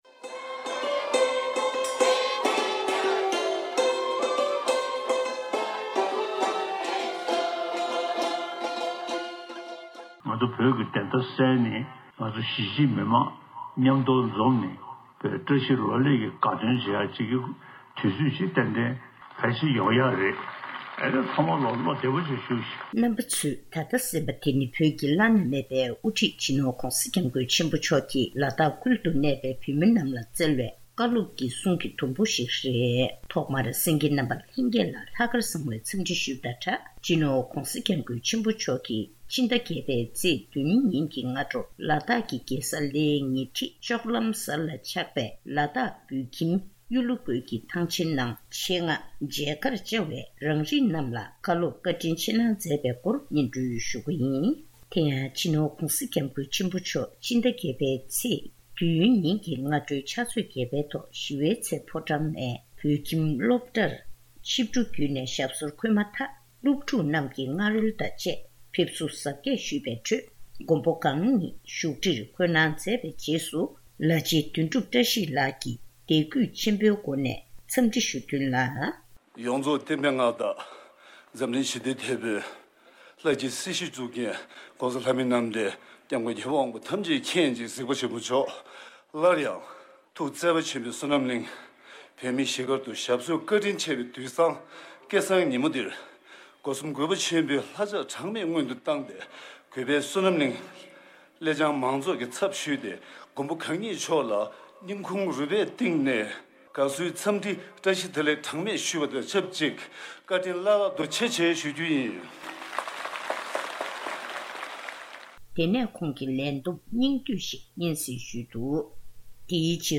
གནས་ཚུལ་ཕྱོགས་བསྒྲིགས་དང་སྙན་སྒྲོན་ཞུས་པར་གསན་རོགས་ཞུ